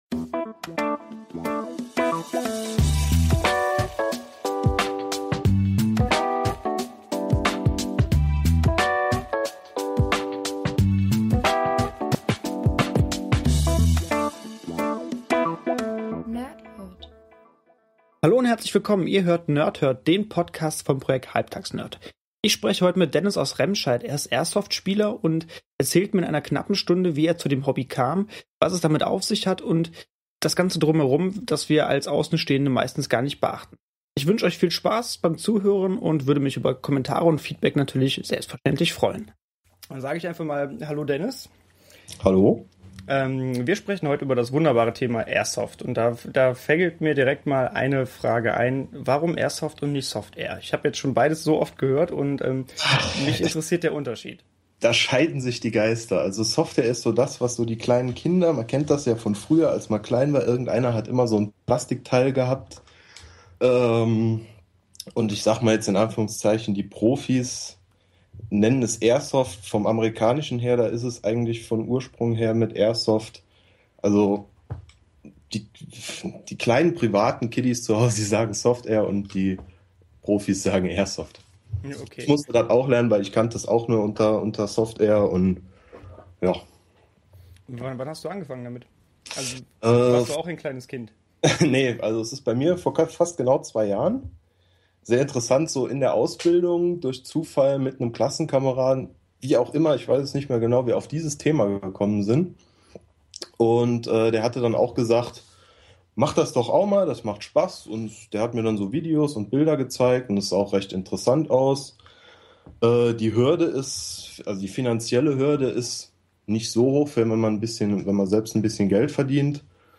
Interviews mit Nerds